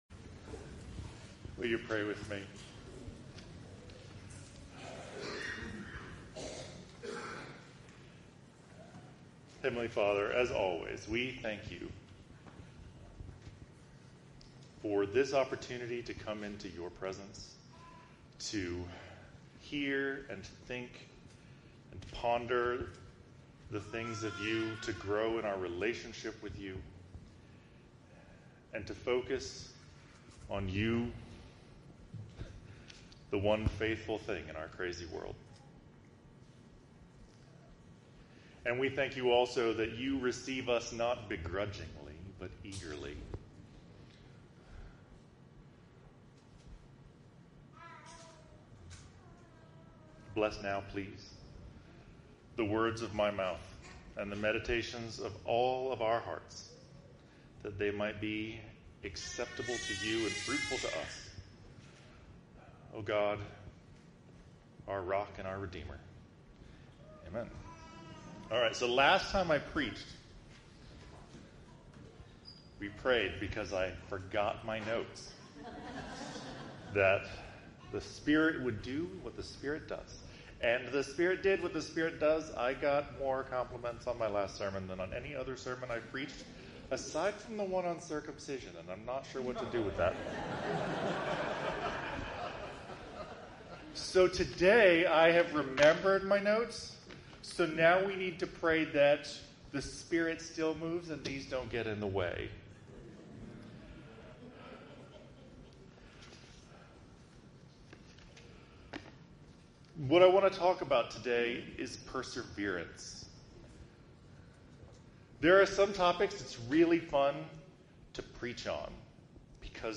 Persevere in Faith - St. Andrew's Anglican Church